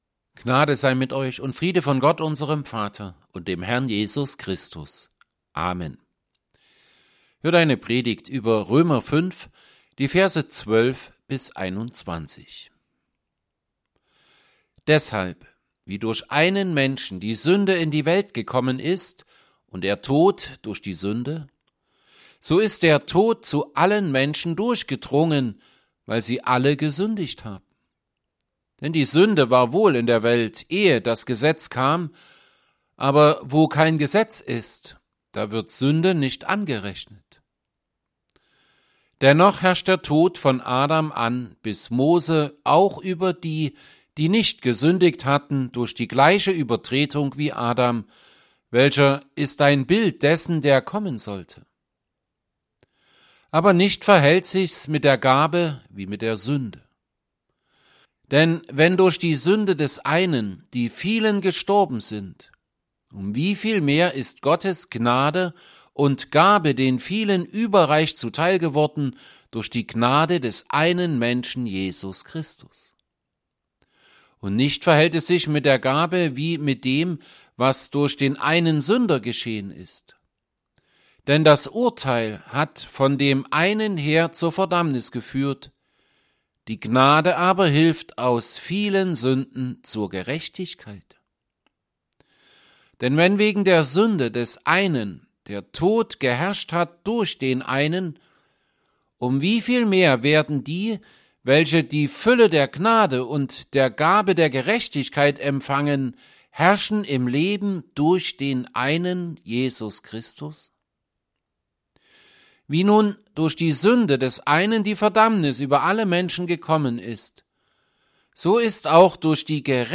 Predigt_zu_Römer_5_12b21.mp3